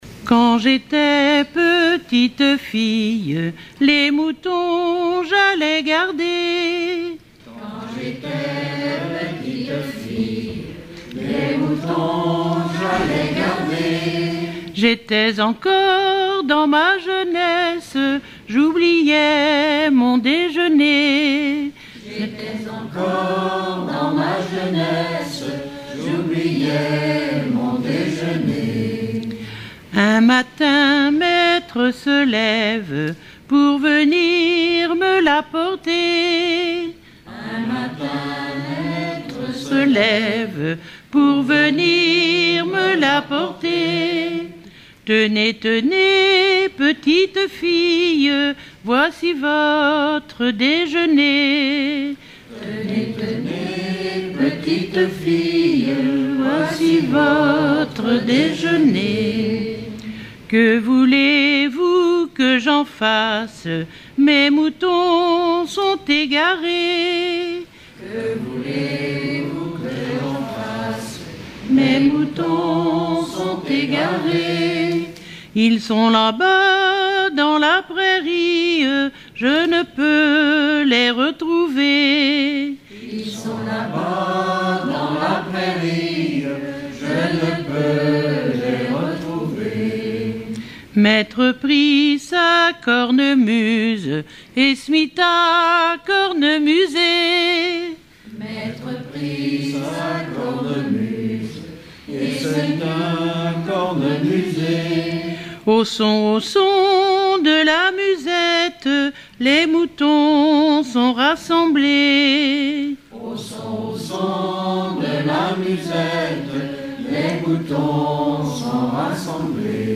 Localisation Saint-Germain-de-Prinçay
Chansons traditionnelles et populaires
Pièce musicale inédite